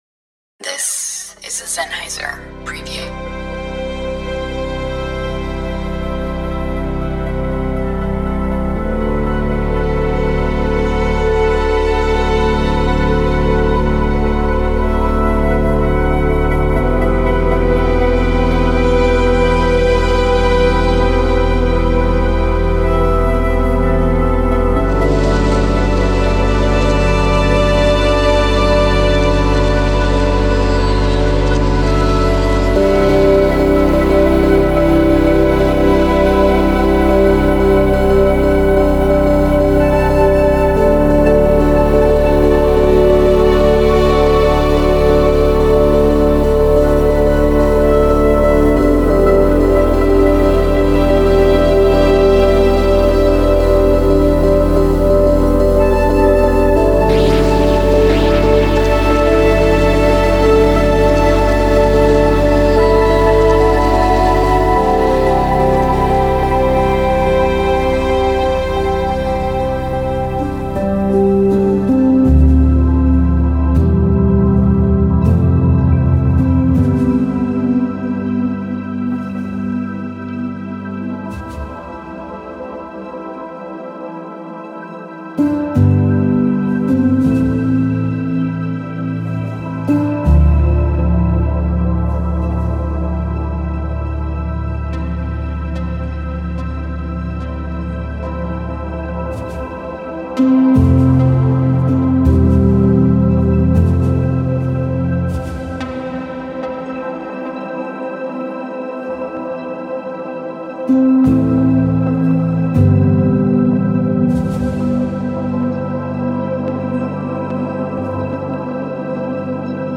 Genre:Ambient
有機的なテクスチャ、親密な録音、そして感情の波と共に呼吸するアナログレイヤー。
音はゆっくりと開き、微妙に変化し、静寂と美しい音の間に佇みます。
ピアノ、ストリングス、ギター、ベース、そして控えめなパーカッションが温かさと意図をもって収録されています。
アナログシンセのトーン、モジュラーの煌めき、ヴィンテージ回路が深みと立体感を与え、人間らしさを失うことはありません。
フィールド録音が全体に織り込まれ、雰囲気を現実に根付かせます。
テクスチャは繰り返すのではなく進化し、メロディはドローンに溶け込み、リズムはパターンというより囁きとして現れます。
アンビエントでありながら、チルアウト、オーガニック、シネマティック、そして実験的でもあります。
デモサウンドはコチラ↓